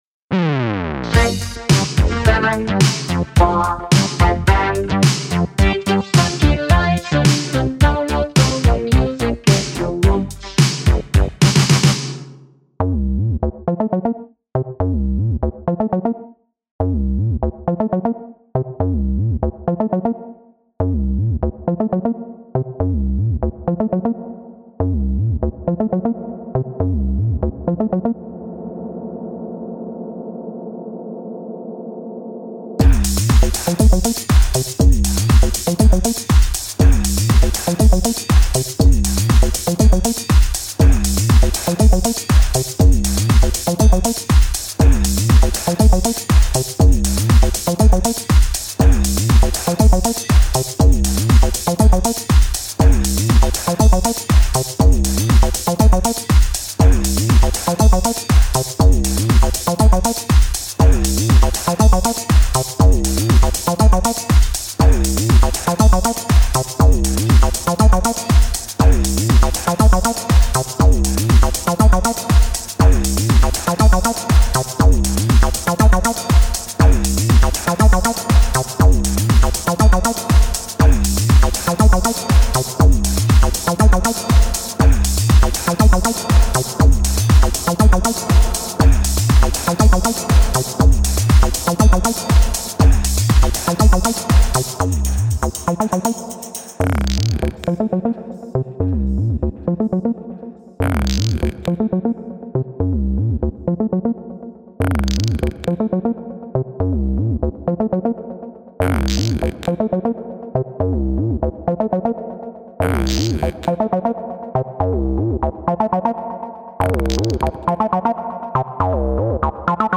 Ich versuche diesmal, mich kurz zu fassen, mit vielen Tracks unter drei Minuten.
Wie gehabt geht es einmal quer (und auch queer) durch die Genres: Acid, DragXFunk, Bonkwave, Kein Bonkwave, Pop, Rock, Indie, Electronic, Klangkunst und zum Ausklang Ambient.